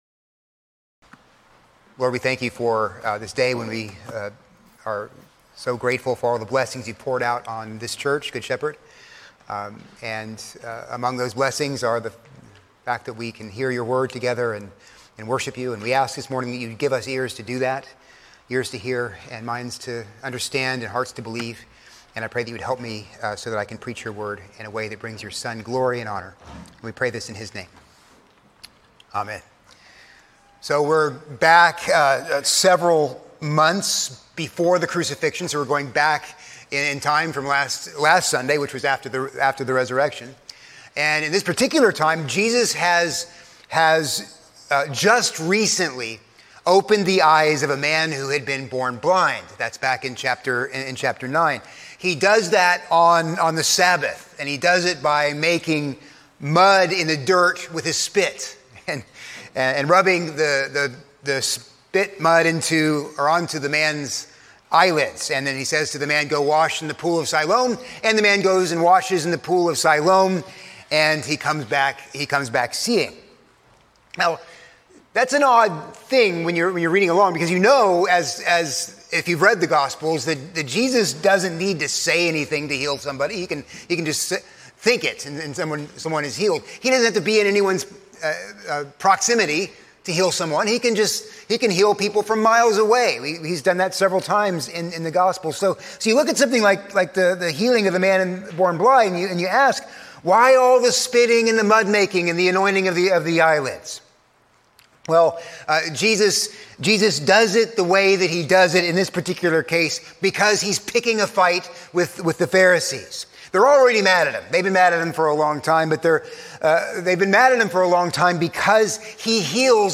A sermon on John 10:22-30